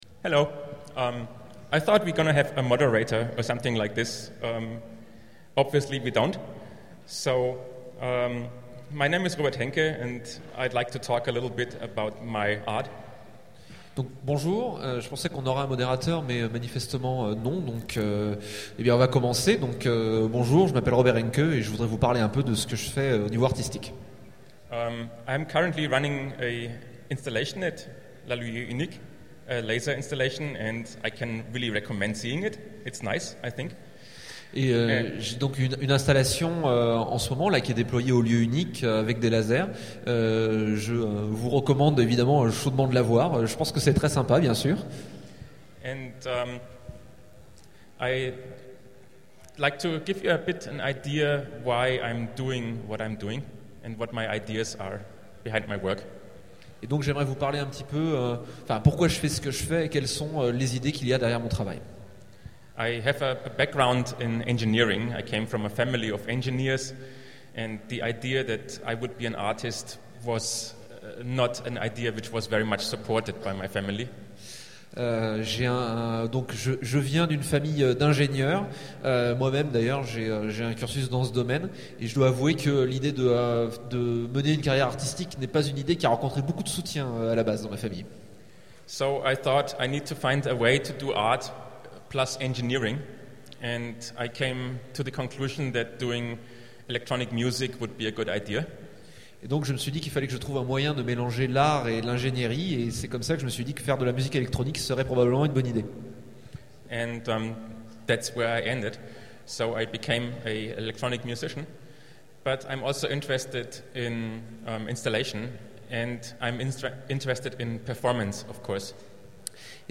Utopiales 12 : Conférence rencontre avec Robert Henke